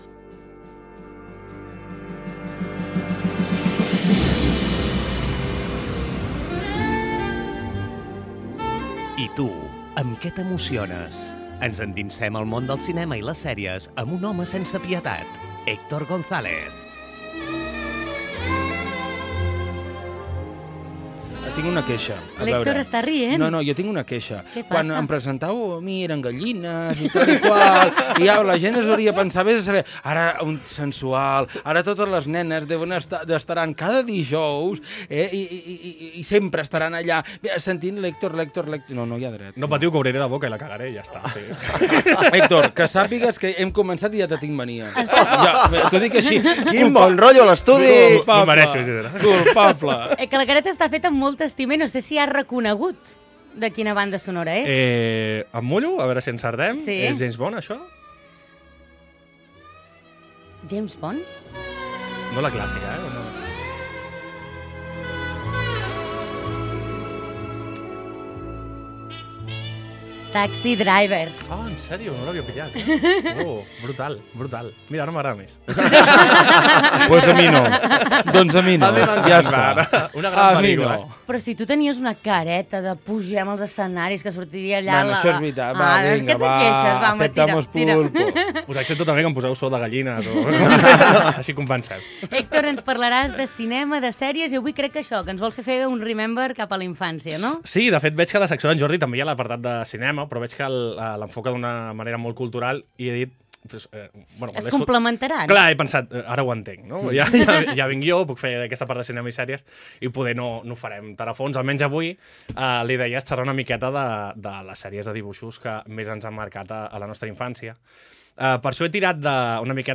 La Tropa Radio “Fem Girona” es una emisora de alcance provincial en la que se realizan programas de todo tipo. Uno de ellos es “La Tropa”, en el que diferentes colaboradores llevamos temas específicos (en mi caso cine y series) y tenemos libertad para hablar de lo que queramos.